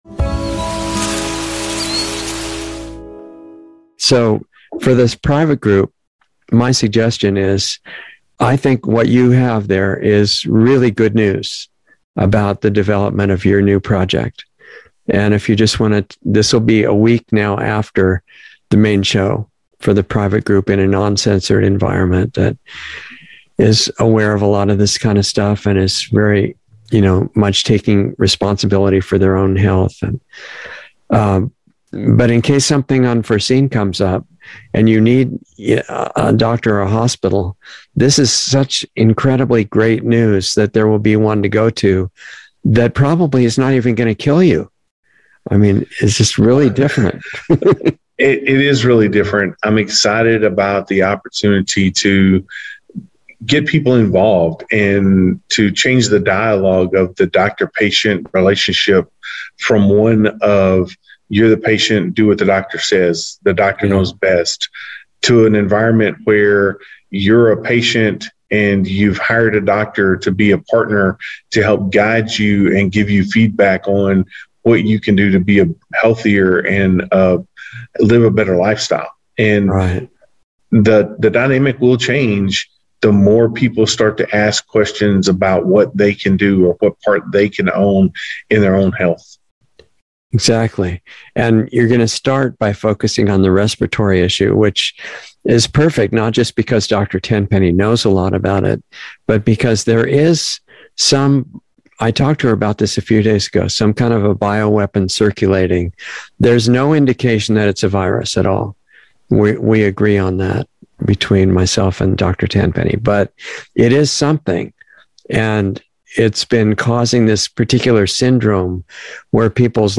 Insider Interview 2/14/22